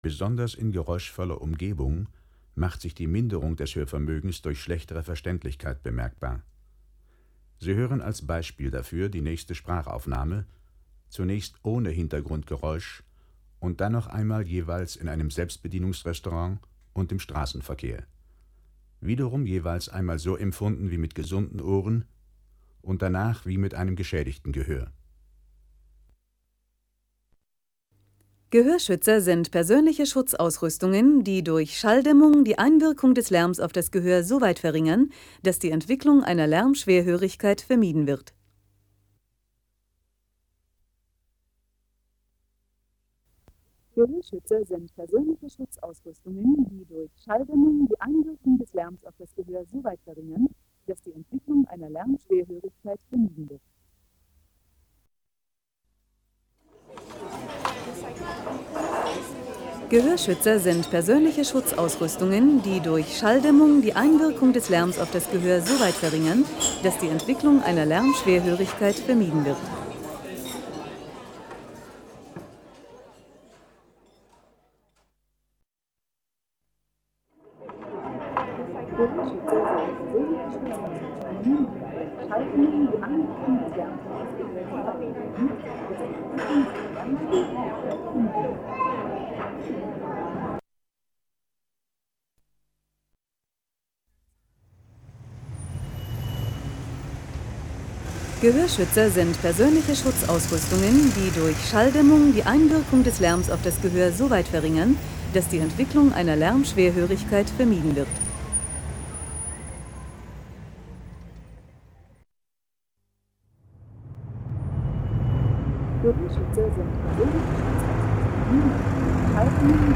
Mit der Lärmpyramide erleben Sie, wie sich eine Schädigung des Gehörs bemerkbar macht. Diese Simulation soll Ihnen die Auswirkungen auf Ihre Wahrnehmung, auf die Lebensqualität und auf Ihr Zusammenleben mit anderen Menschen verdeutlichen.
Unterschied geschädigtes Gehör gegenüber Gesundem, Hörbeispiel 2 (mp3-Datei)